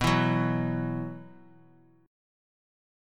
Bsus4 chord